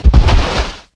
hit_ground.ogg